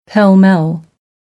Používá se i ve Spojených státech jako
pell-mell_US.mp3